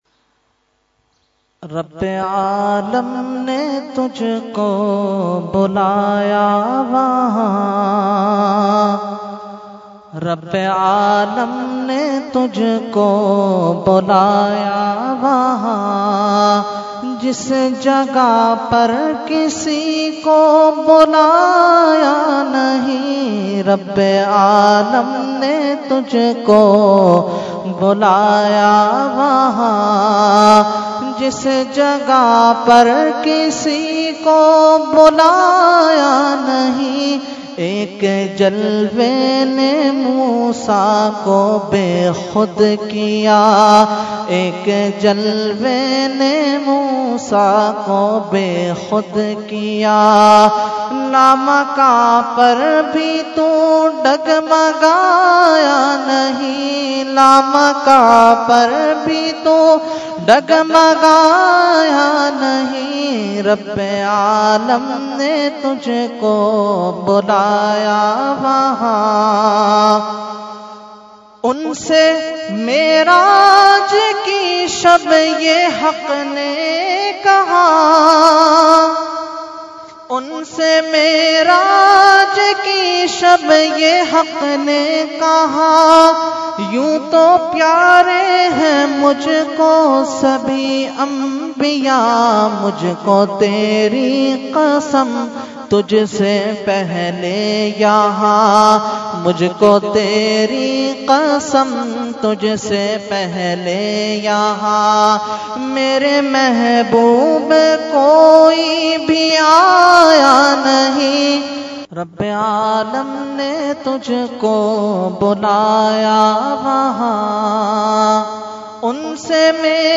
Category : Naat | Language : UrduEvent : Shab e Meraj 2016